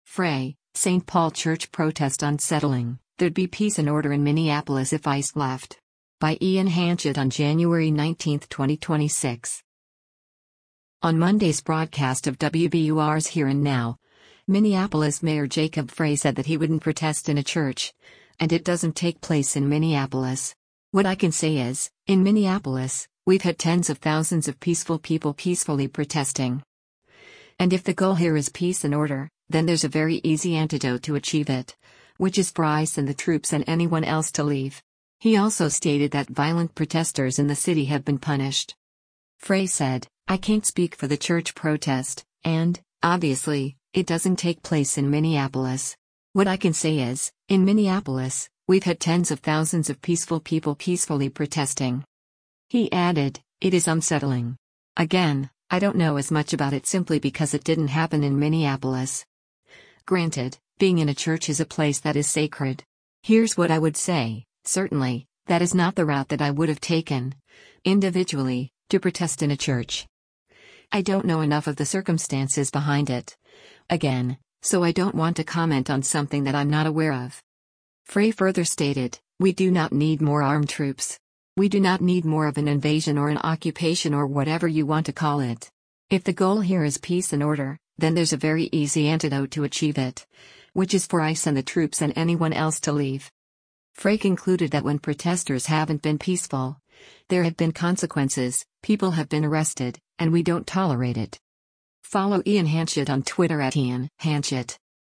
On Monday’s broadcast of WBUR’s “Here and Now,” Minneapolis Mayor Jacob Frey said that he wouldn’t protest in a church, and “it doesn’t take place in Minneapolis. What I can say is, in Minneapolis, we’ve had tens of thousands of peaceful people peacefully protesting.”